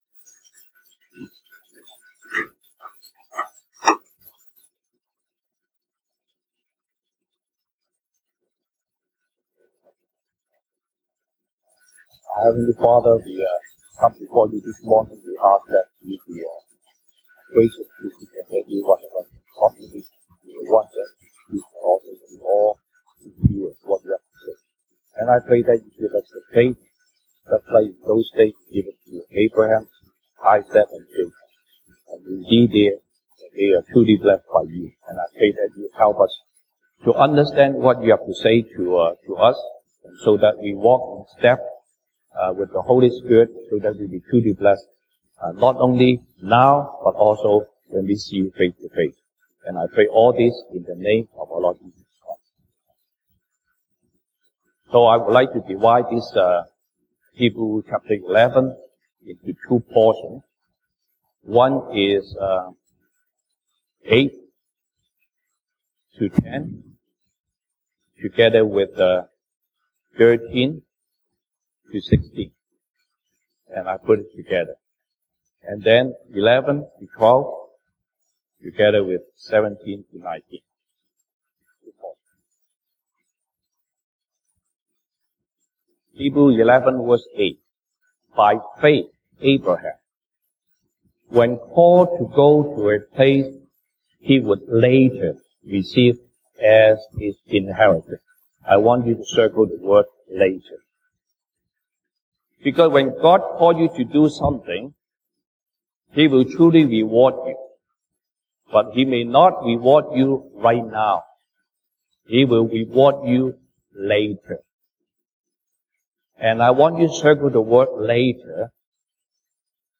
Sunday Service English Topics: Abraham , Faith , God's calling « 以西結書5:1-5:17 啟示錄2:18-2:29 »